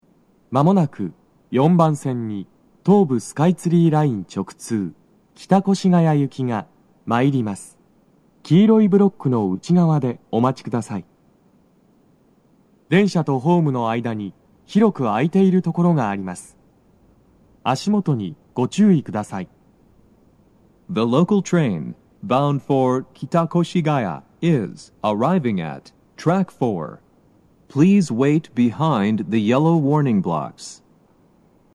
鳴動は、やや遅めです。
接近放送1
hkasumigaseki4sekkinkitakoshigaya.mp3